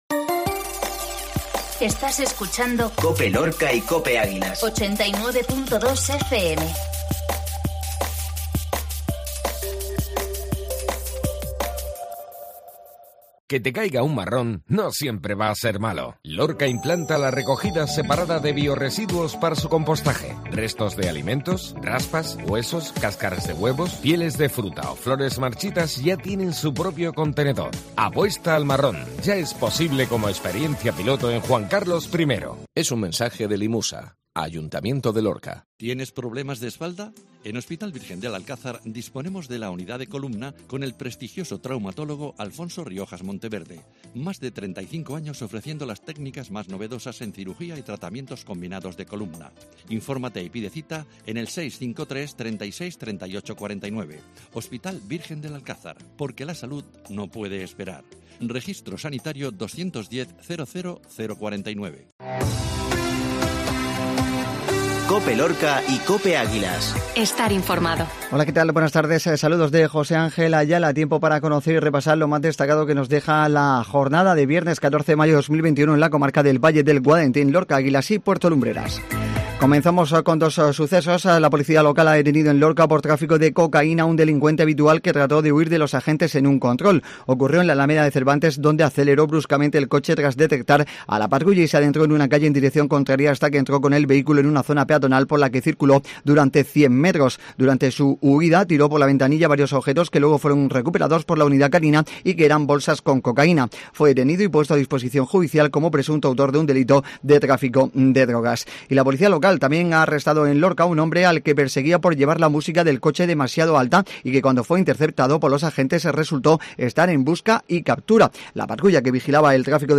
INFORMATIVO MEDIODÍA COPE VIERNES